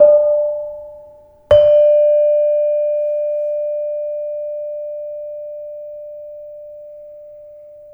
Bonang Barung Pl1 & Saron Demung Pl1 of Kyai Parijata